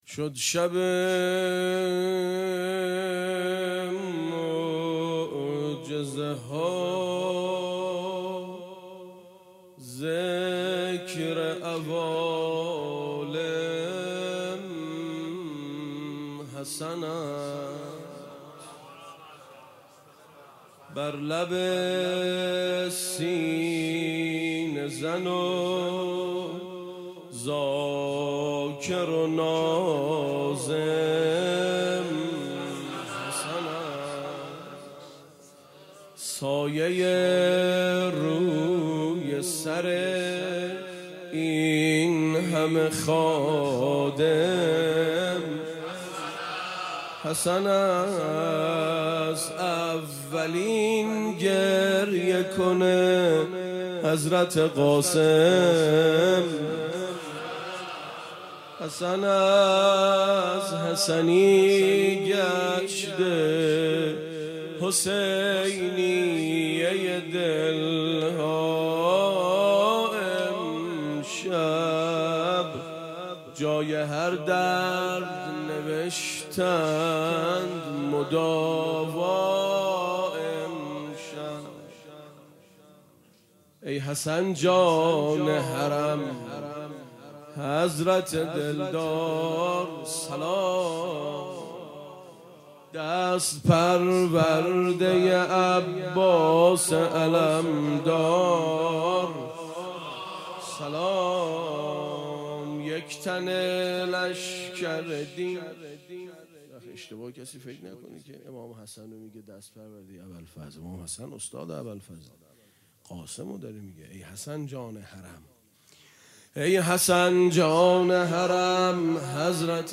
خیمه گاه - روضةالشهداء - مدح حضرت قاسم (ع)
شب ششم محرم ۱۴۴۴/ ۱۲ مرداد ۴۰۱